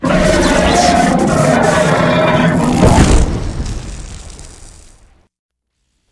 bigdie2.ogg